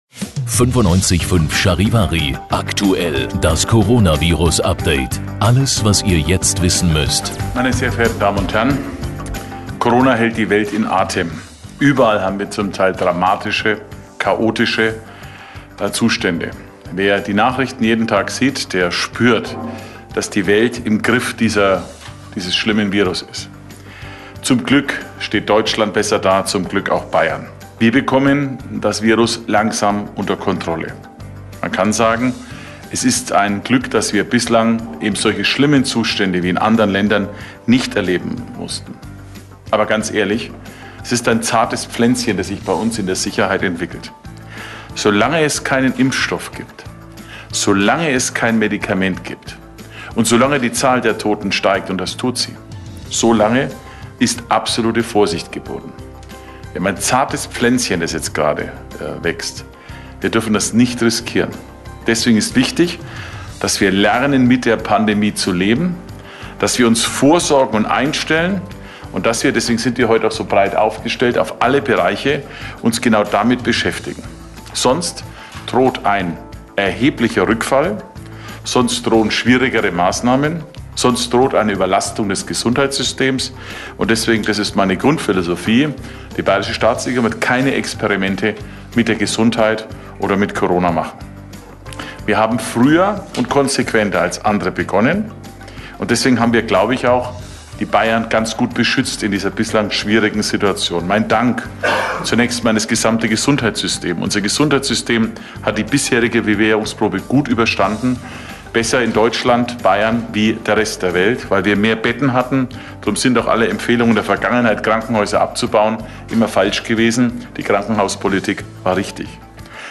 Zum-Nachhoeren-Die-Pressekonferenz-von-Soeder-16-April.mp3